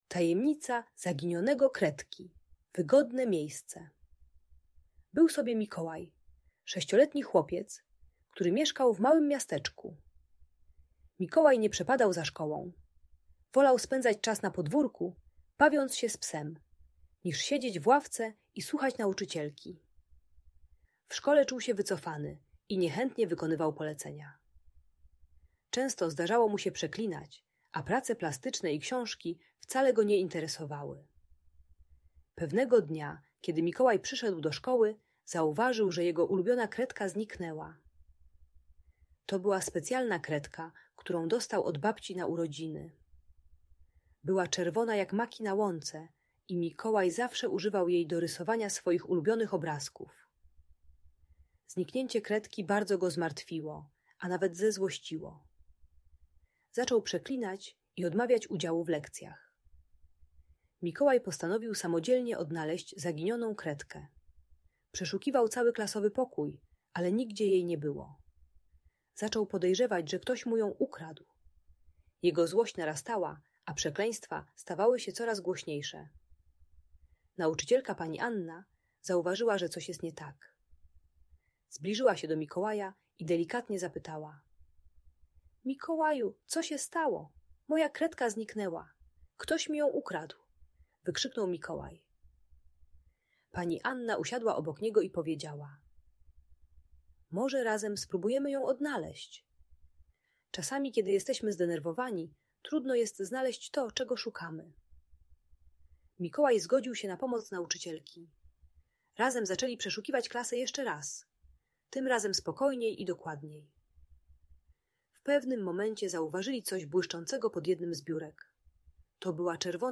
Tajemnica Zaginionego Kredki - Audiobajka dla dzieci